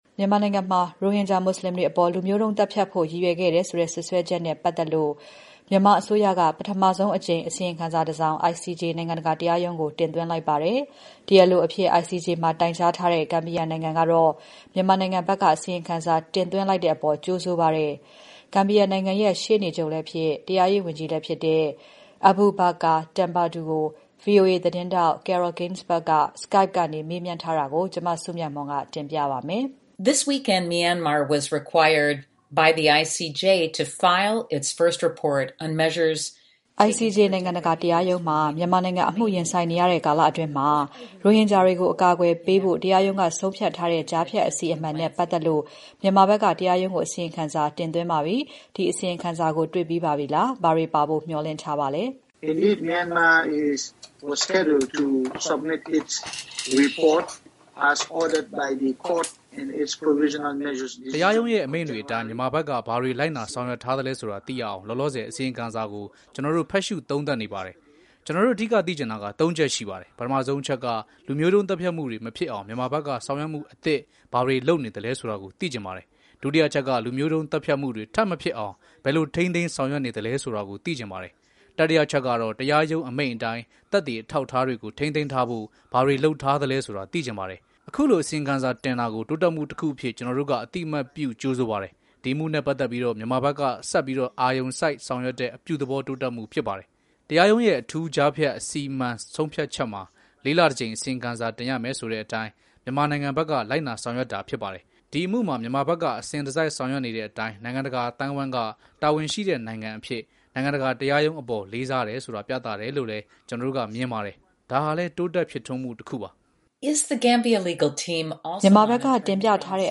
ဂမ်ဘီယာတရားရေးဝန်ကြီး Abubacarr Tambadou